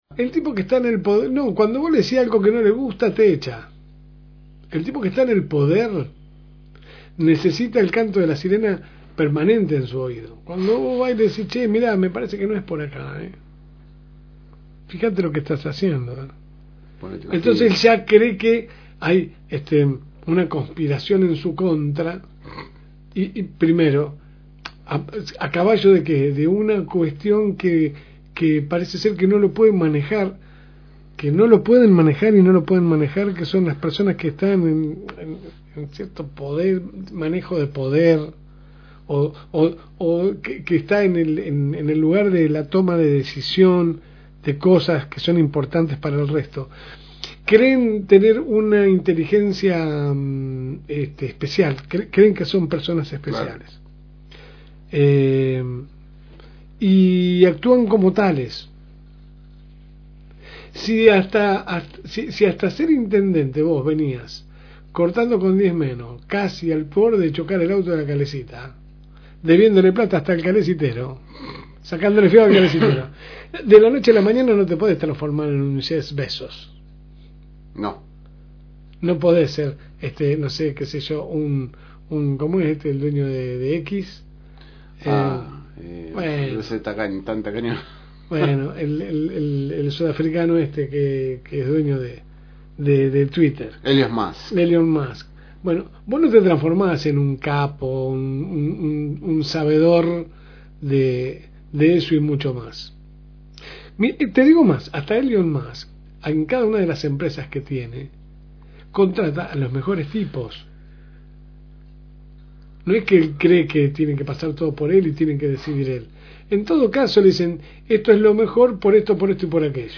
AUDIO – Editorial de La Segunda Mañana – FM Reencuentro
La podes escuchar completa acá o de lunes a viernes de 10 a 12 HS por el aire de la FM Reencuentro.